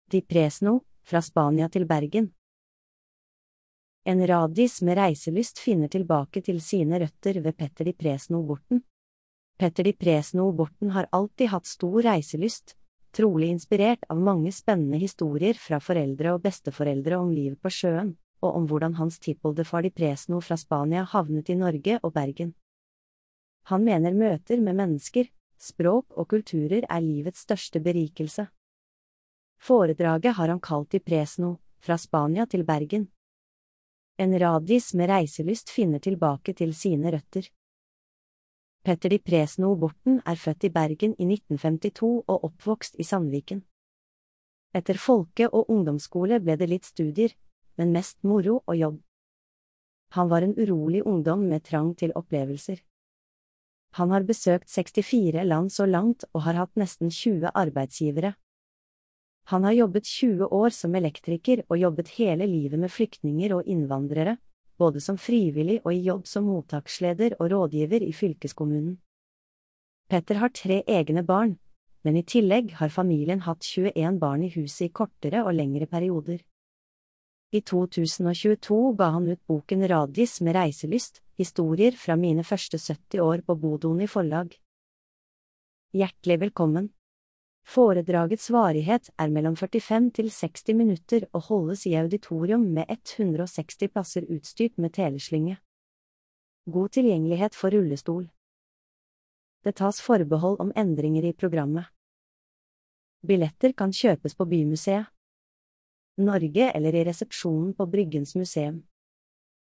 Populærvitenskapelige foredrag med et vidt spenn av tema i auditoriet på Bryggens Museum.